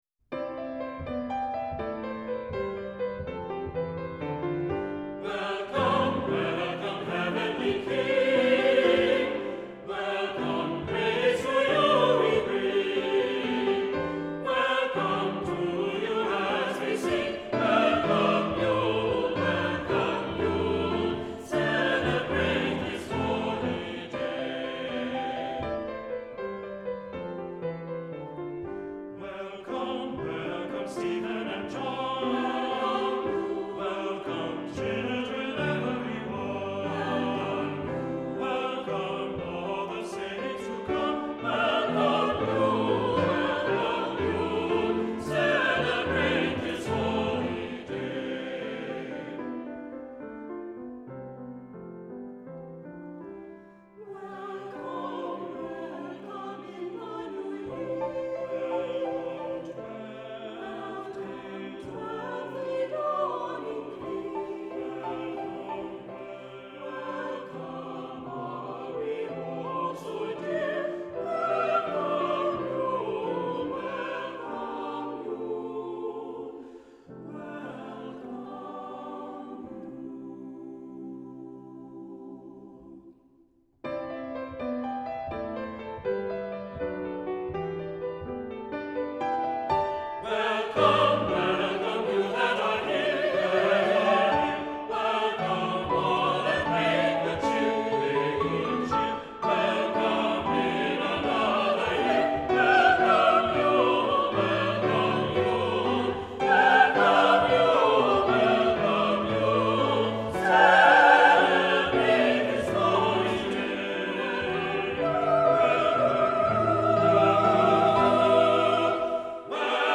Accompaniment:      Keyboard
Music Category:      Choral